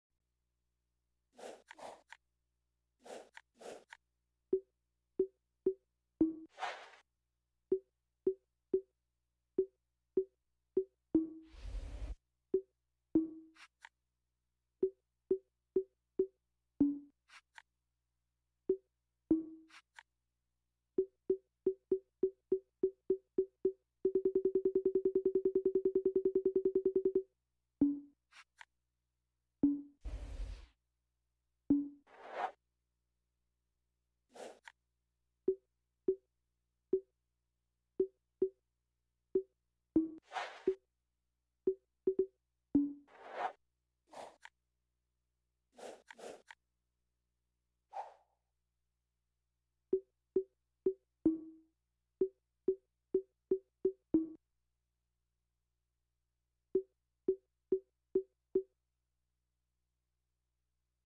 Who Remembers the iconic sounds of the OG blades dashboard for the Xbox 360